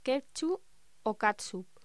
Locución: Ketchup o catsup